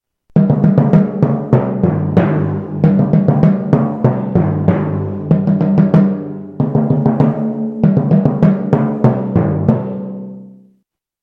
timbales.mp3